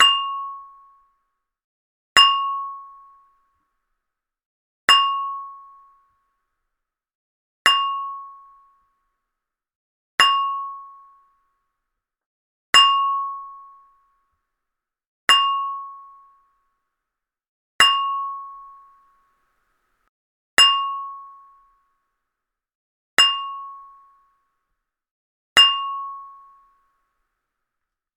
Elevator Bells
Bell Ding Elevator Loud Ring sound effect free sound royalty free Sound Effects